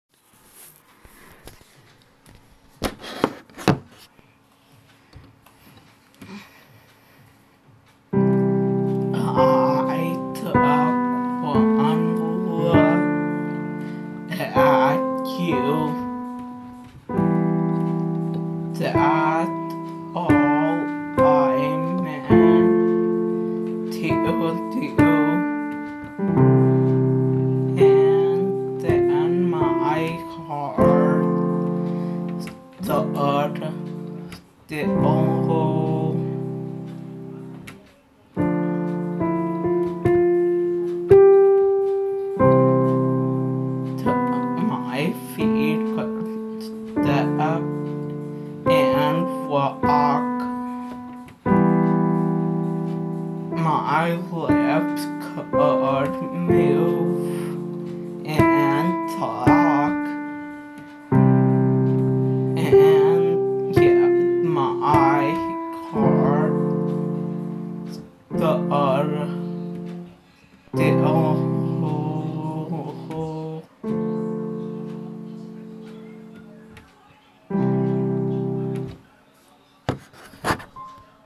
here's me singing and playing ofc I'm still not very good